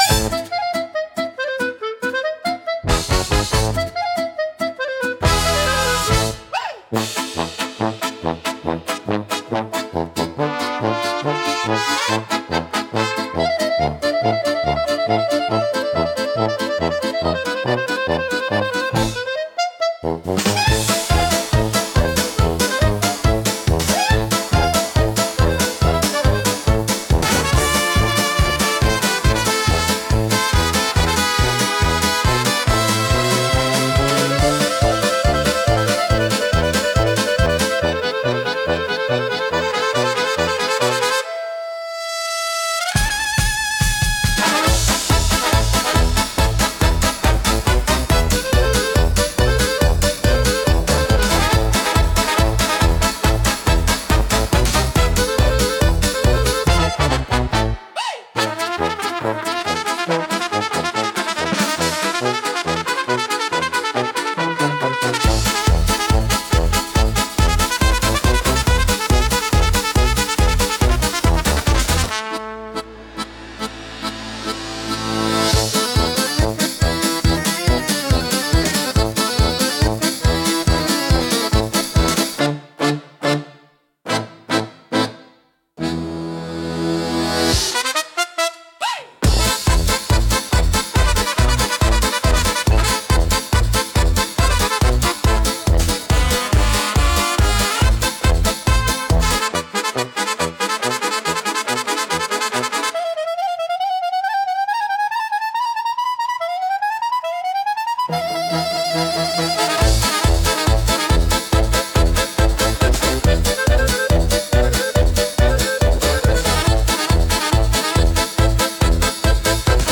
Instrumental / 歌なし